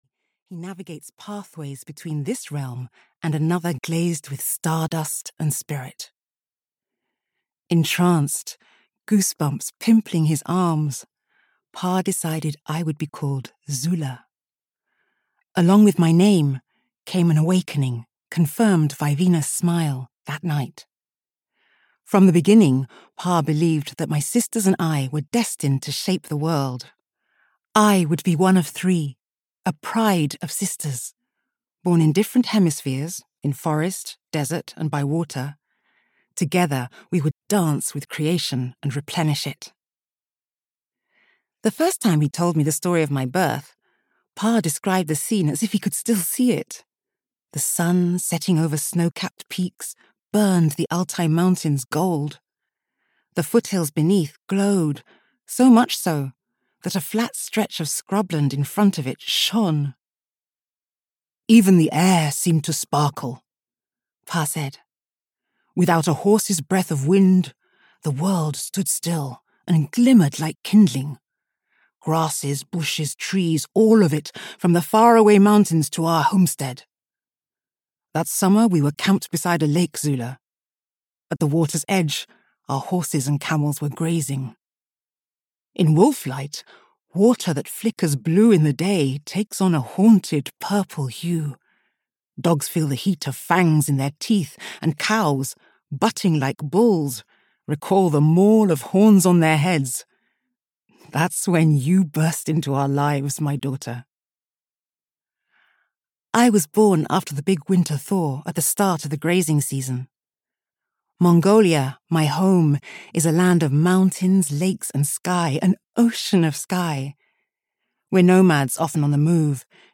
Wolf Light (EN) audiokniha
Ukázka z knihy